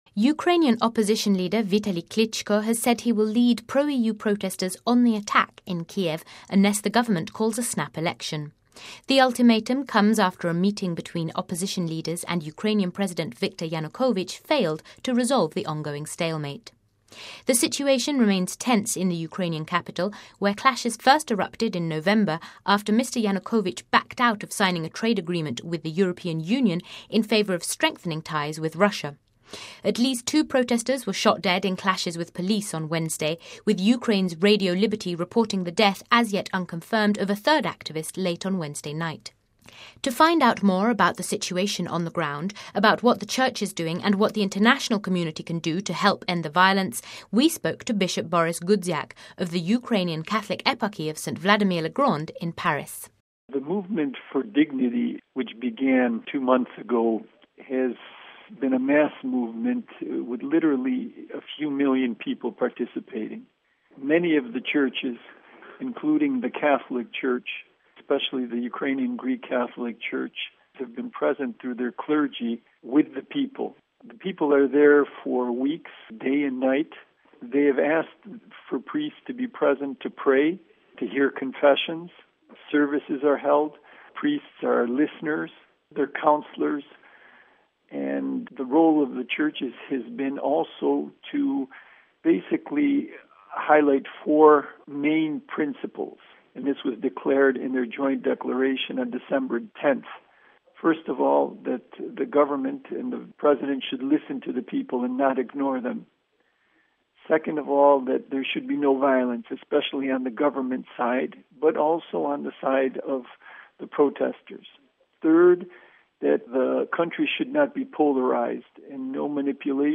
To find out more about the situation on the ground, about what the church is doing and what the international community can do to help end the violence, we spoke to Bishop Borys Gudziak, of the Ukrainian Catholic Eparchy of Saint Wladimir-Le-Grand de Paris.